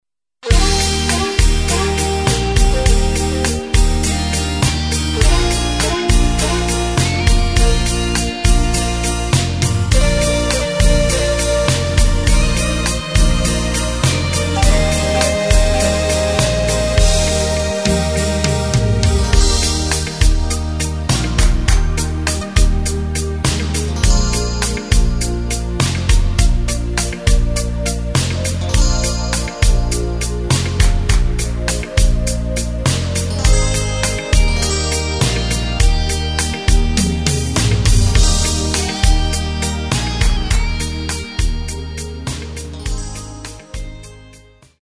Takt:          4/4
Tempo:         102.00
Tonart:            Eb
Schlager aus dem Jahr 2017!